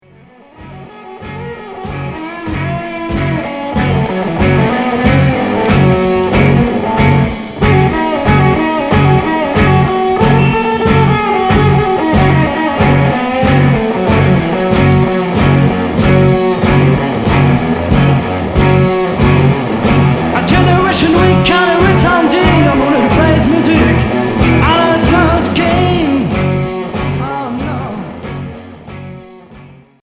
Heavy rock psyché Premier 45t retour à l'accueil